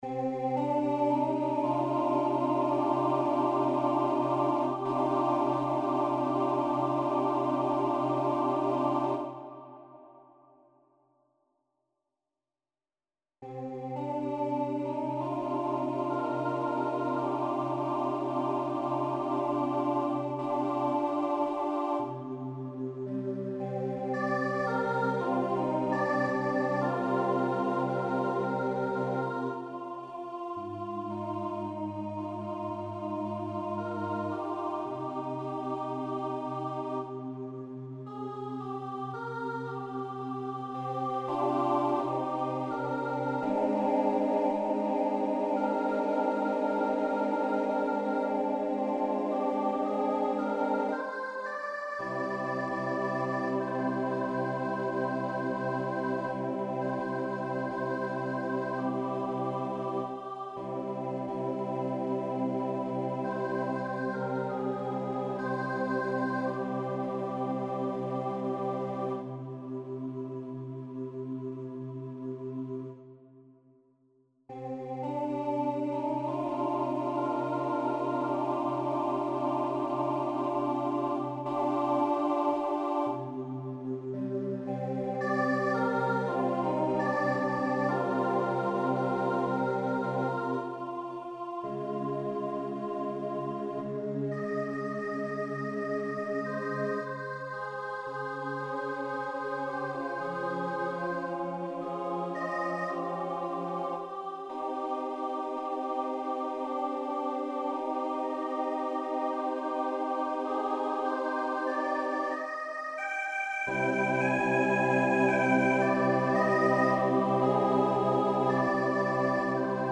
Forces: SAATTB + soprano solo (minimal A and T divisi)